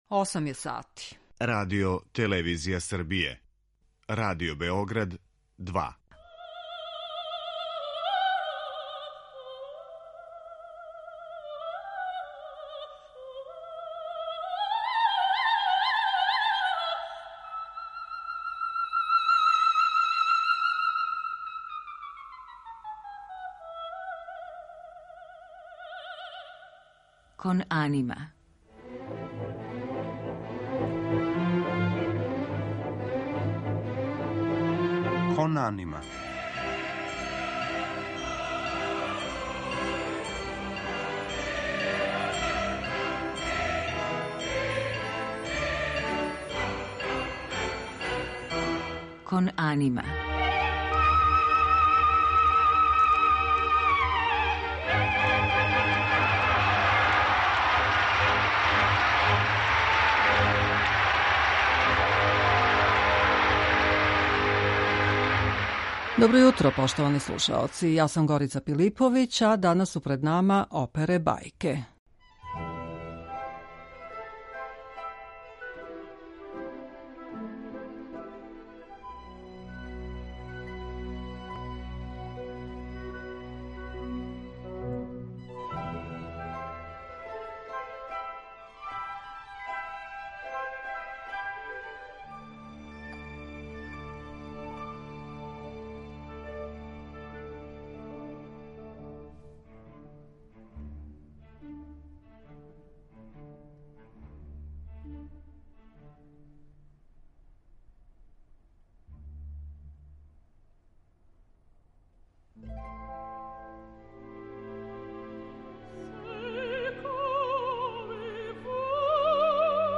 У данашњој емисији Кон анима можете слушати одломке одабраних примера